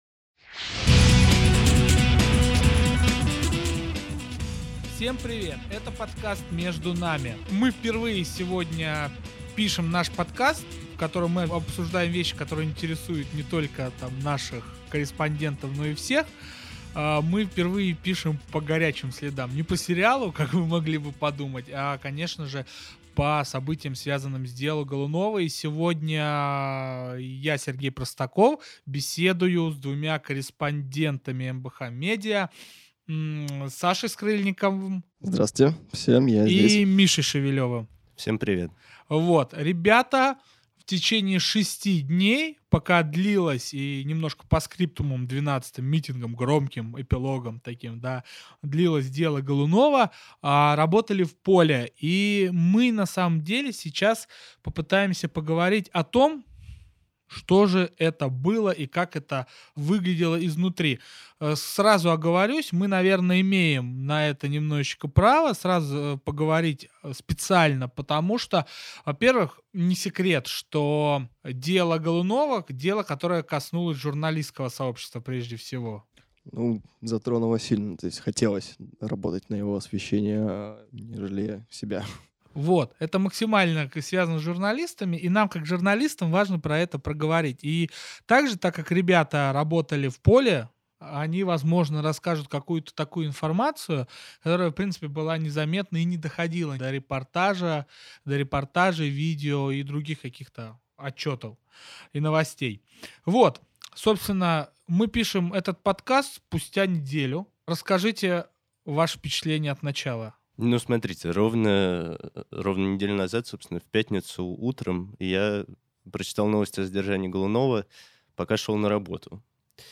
Этот подкаст — рассказ журналистов «МБХ медиа» о деле Голунова.